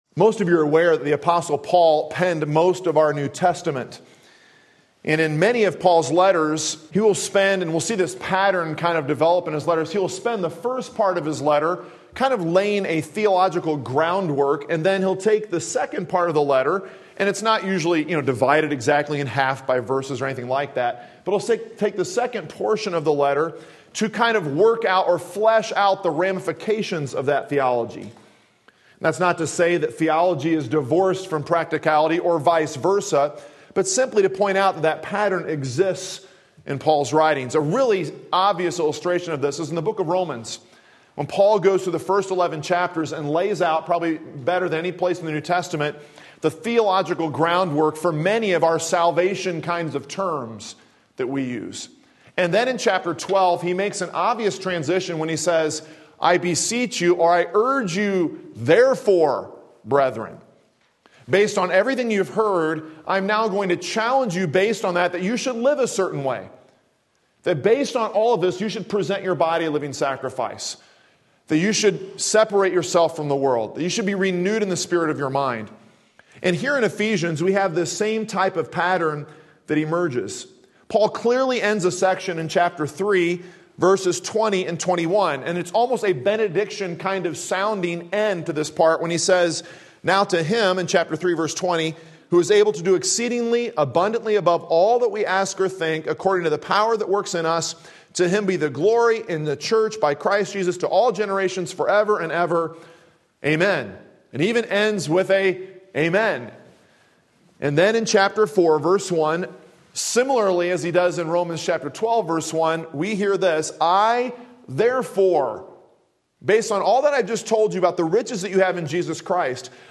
Biblical Handling of Anger Ephesians 4 Sunday Morning Service